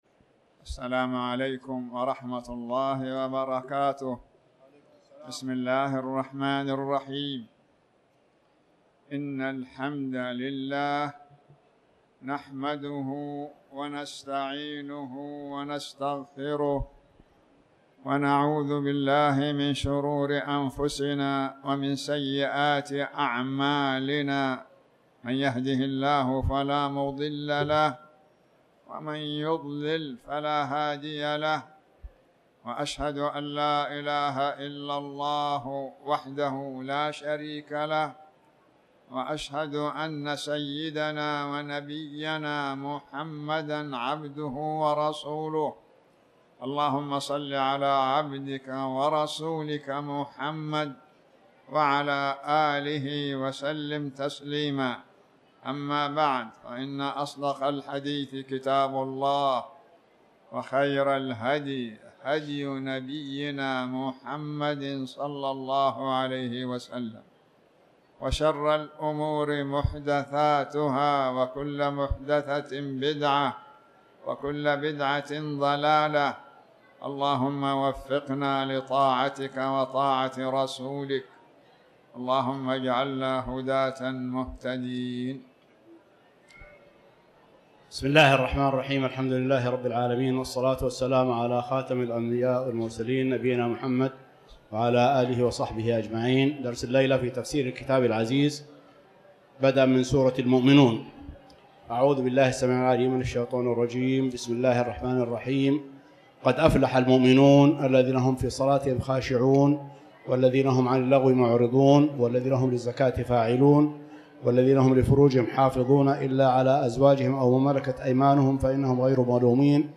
تاريخ النشر ٩ شعبان ١٤٣٩ هـ المكان: المسجد الحرام الشيخ